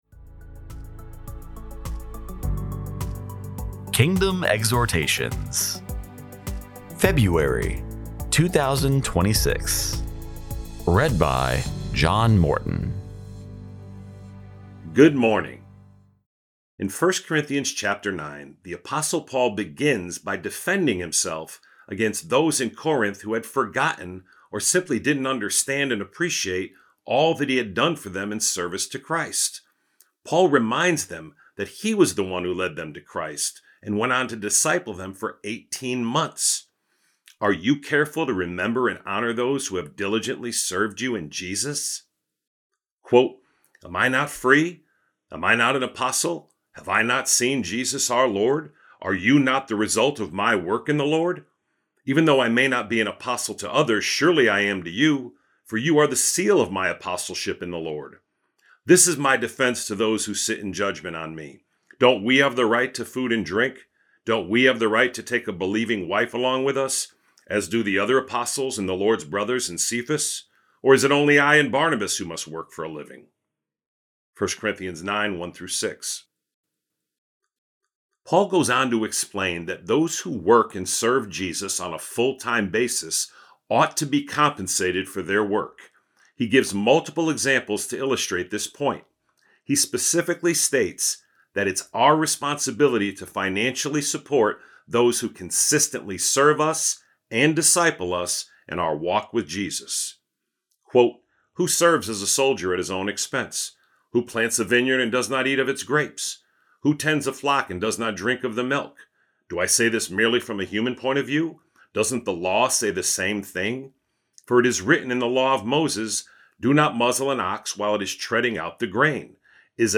Exhortation for February 2026